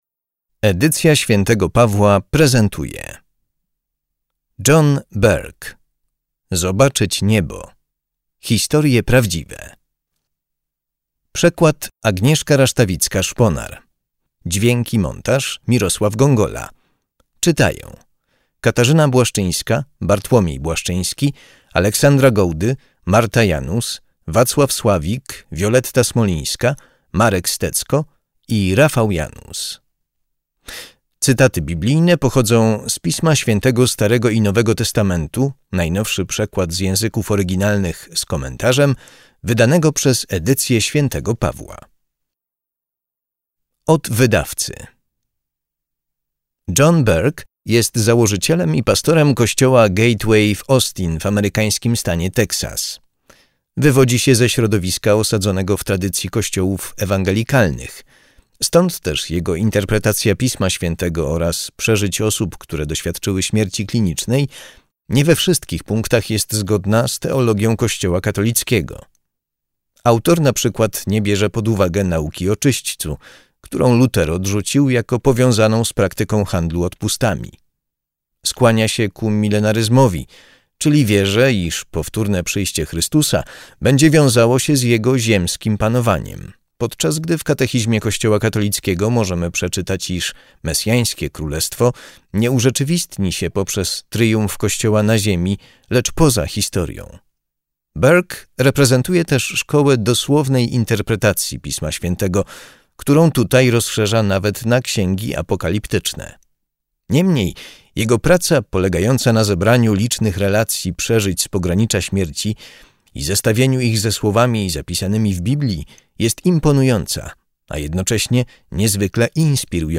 Bestseller New York Timesa w wykonaniu grona znakomitych aktorów.
Zobaczyć niebo - bestseller New York Timesa - już do słuchania w formie audiobooka.